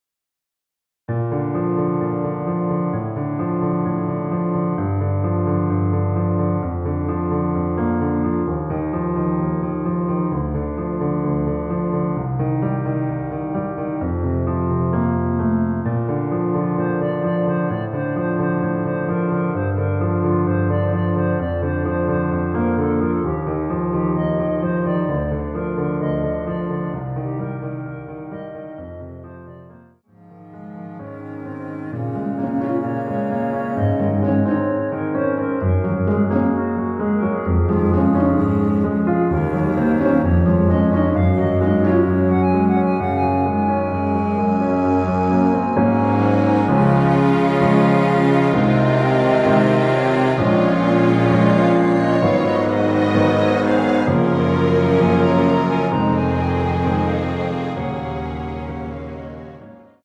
원키에서(-2)내린 멜로디 포함된 MR입니다.(미리듣기 확인)
Bb
앞부분30초, 뒷부분30초씩 편집해서 올려 드리고 있습니다.
중간에 음이 끈어지고 다시 나오는 이유는